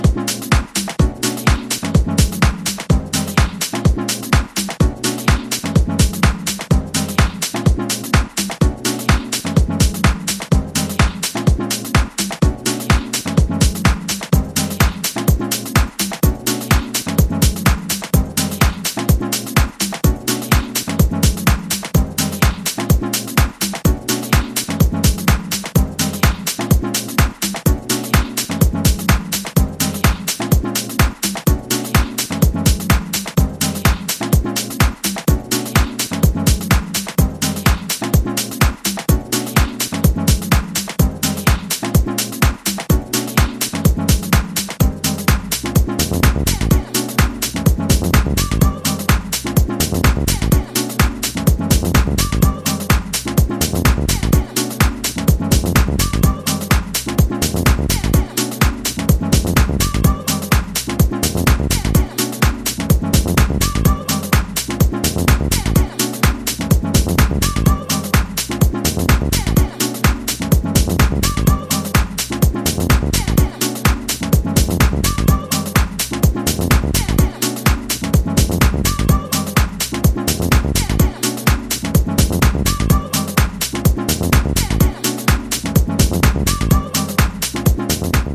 underground house